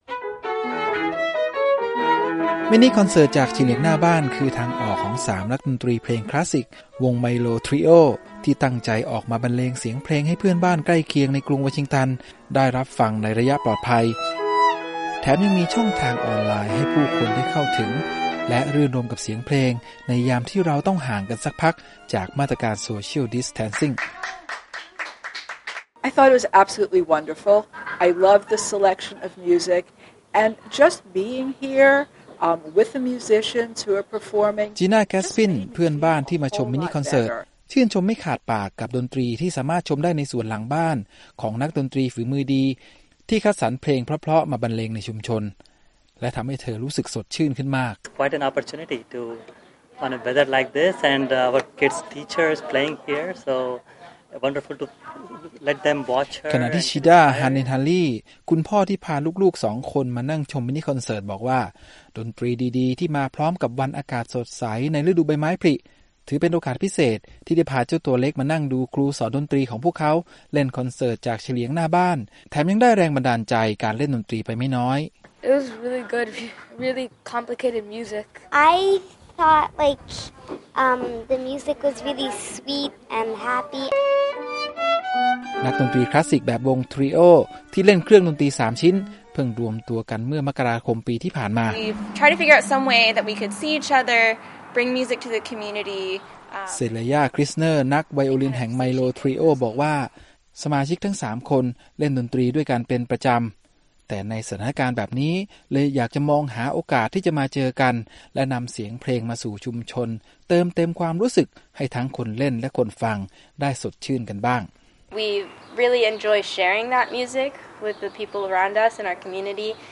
นักดนตรีผู้รักในเสียงเพลงชาวอเมริกันจัดฟรีคอนเสริ์ตเล่นดนตรีจากบนระเบียงบ้านSocially Distant Concert ใจกลางย่านที่พักอาศัยในกรุงวอชิงตันเพื่อสร้างบรรยากาศดีๆแบ่งปันให้คนในชุมชน และส่งให้กำลังใจผู้คนผ่านสื่อออนไลน์ ท่ามกลางข้อจำกัดในช่วงการประกาศคำสั่งห้ามออกเคหะสถานในภาวะการระบาดของเชื้อโคโรนาไวรัส